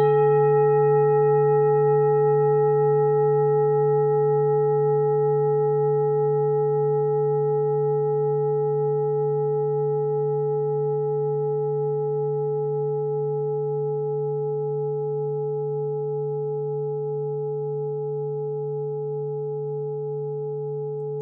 Klangschale TIBET Nr.32
Sie ist neu und ist gezielt nach altem 7-Metalle-Rezept in Handarbeit gezogen und gehämmert worden..
(Ermittelt mit dem Filzklöppel)
Der Marston liegt bei 144,72 Hz, das ist nahe beim "D".
klangschale-tibet-32.wav